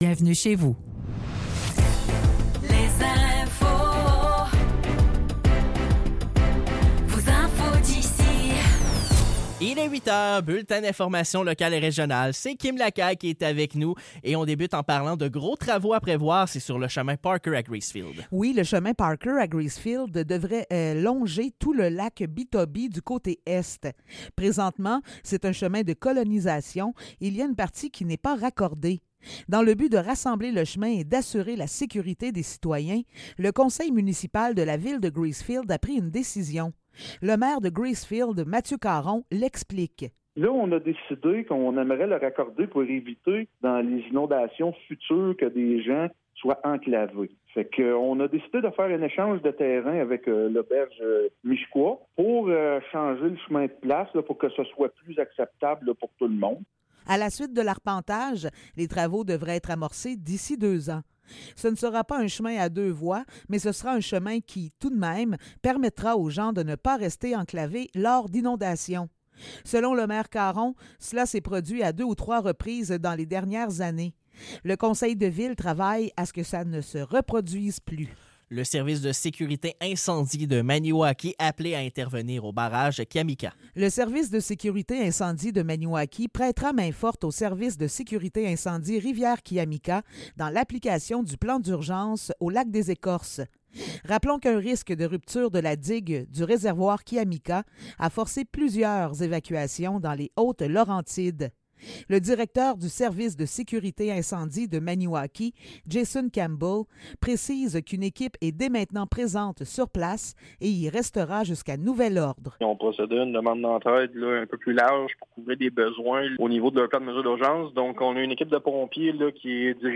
Nouvelles locales - 7 décembre 2023 - 8 h